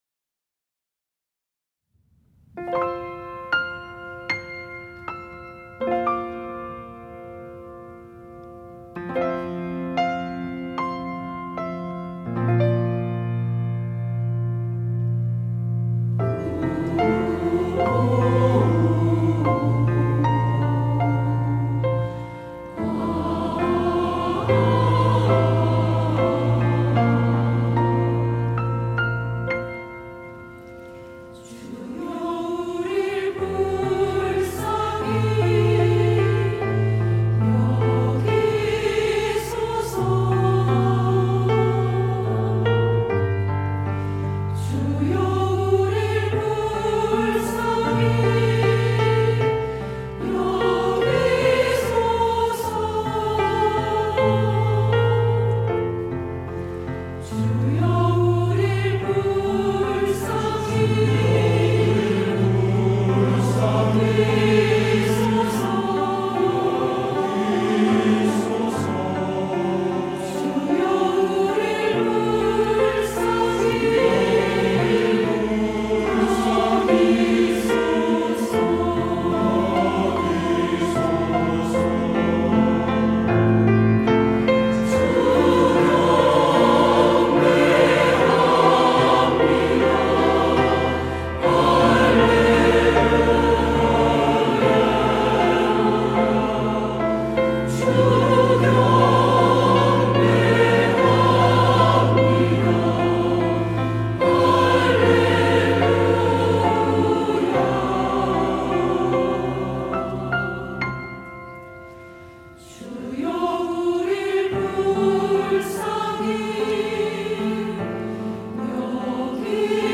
시온(주일1부) - 주여 우리를 불쌍히 여기소서
찬양대